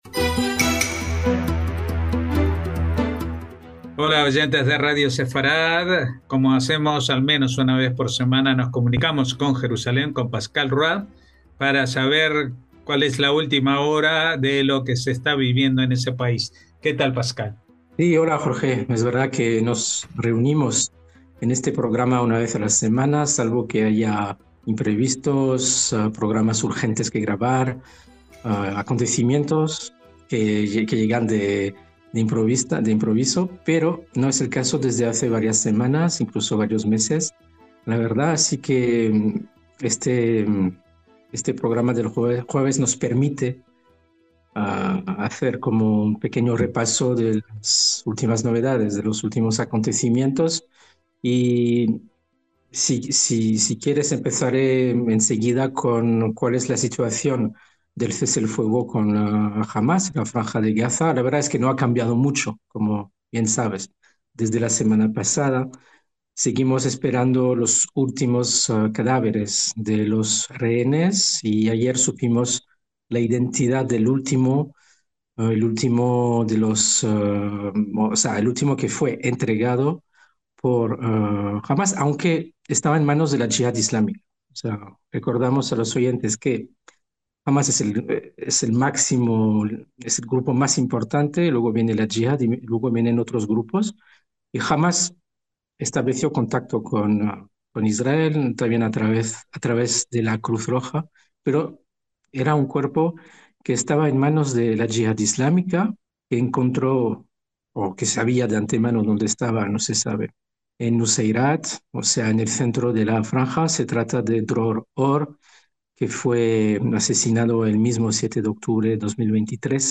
NOTICIAS CON COMENTARIO A DOS - Esta semana Israel volvió a conmocionarse con la llegada del antepenúltimo de los cadáveres que quedan por recuperar.